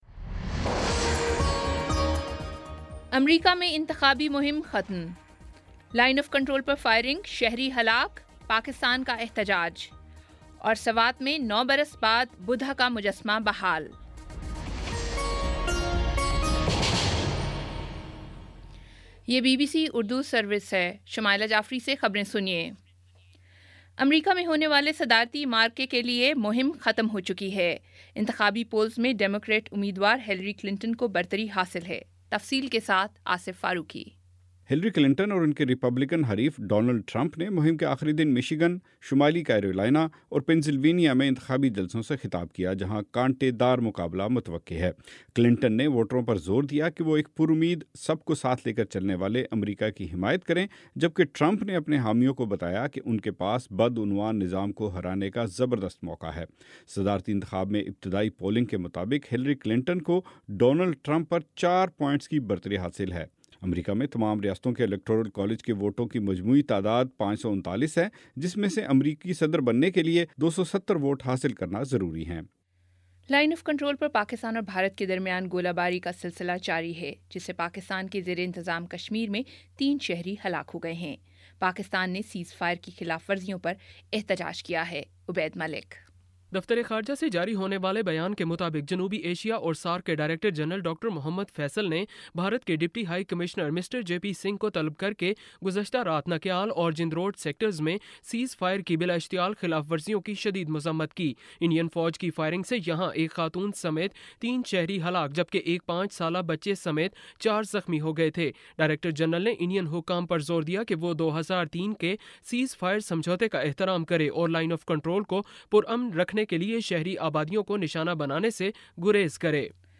نومبر 08 : شام پانچ بجے کا نیوز بُلیٹن